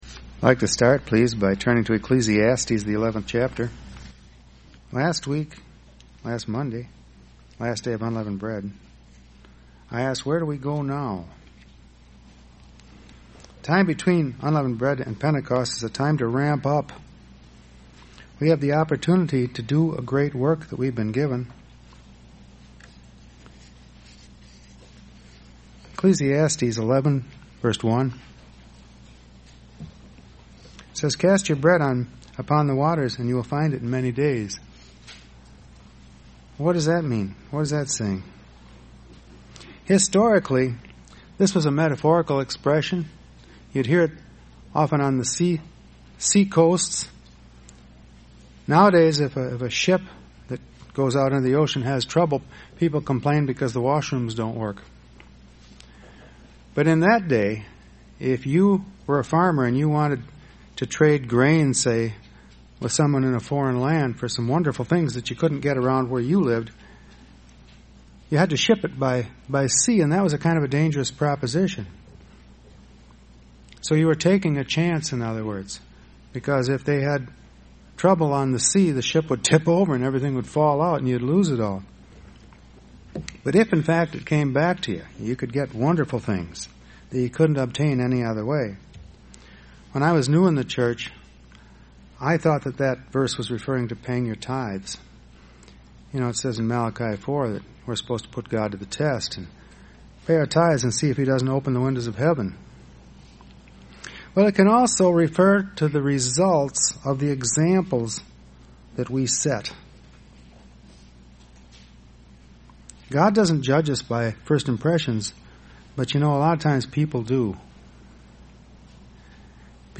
UCG Sermon Studying the bible?
Given in Beloit, WI